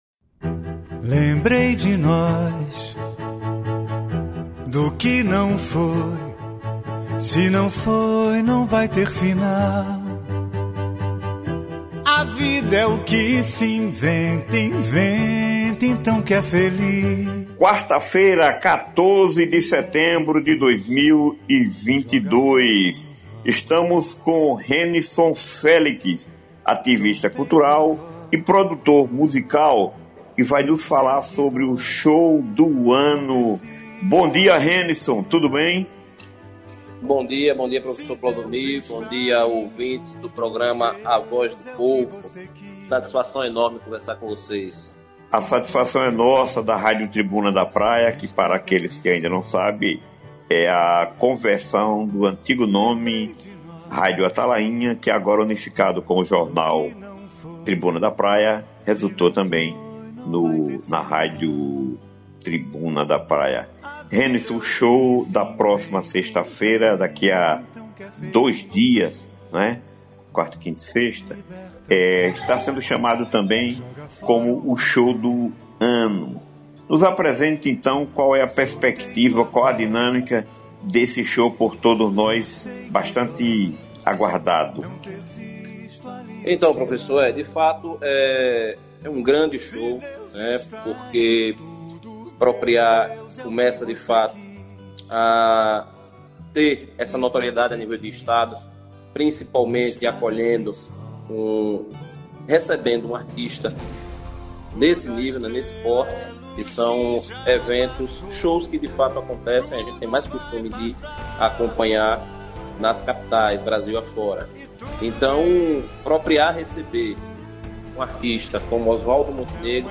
Confira abaixo o áudio disponibilizado pela produção do programa para a redação do jornal: